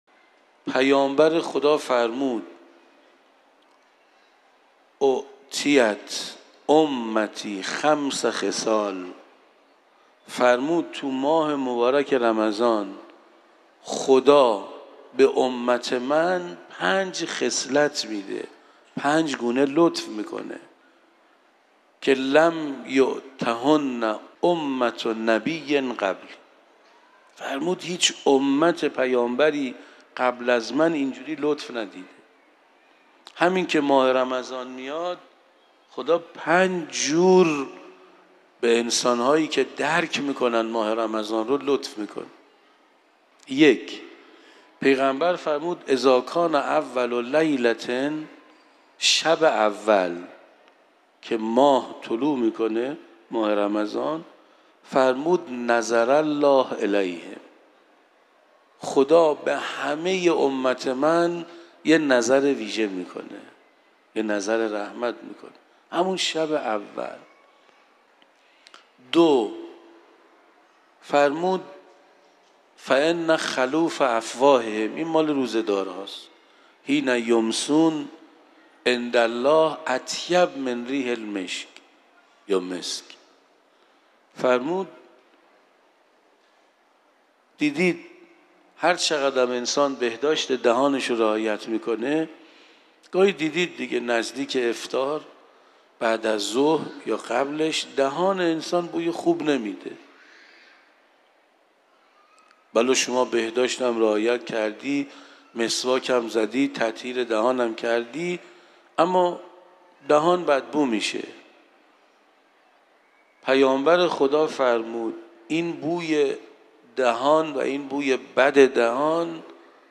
در حرم مطهر رضوی